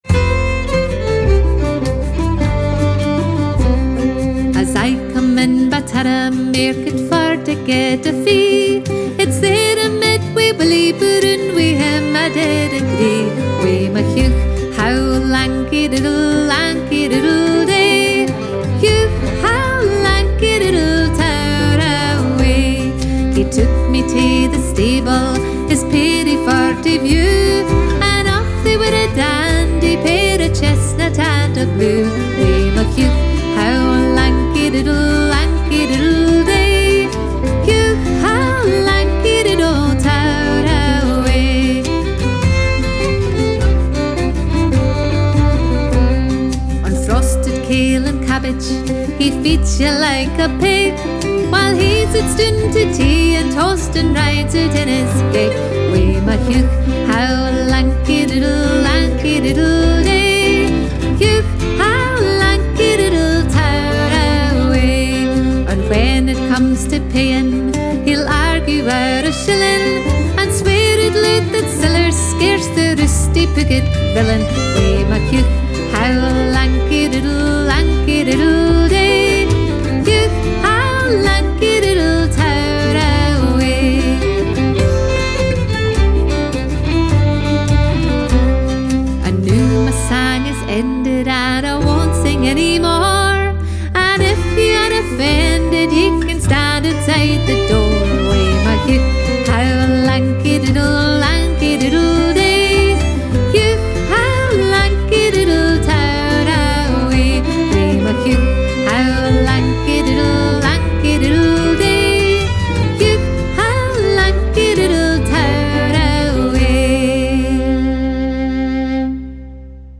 Scottish Music Download Turra Mairket MP3